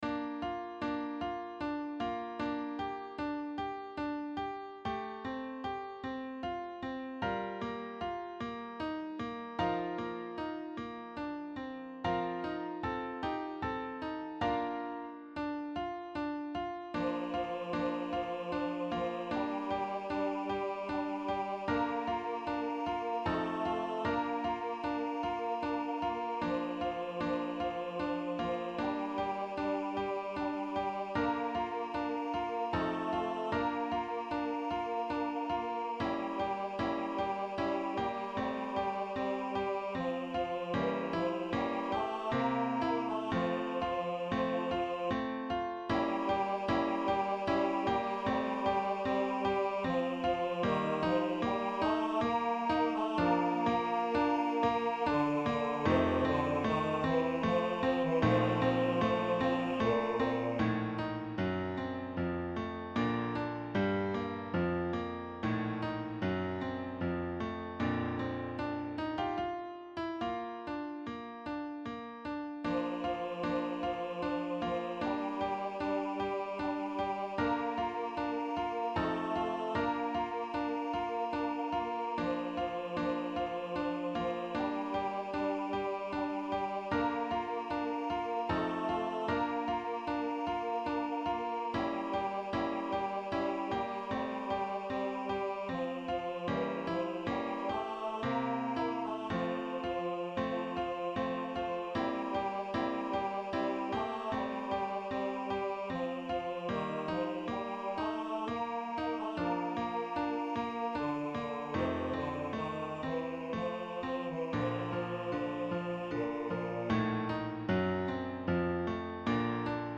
SINGEN: Lieder und Arien für Bass/Bariton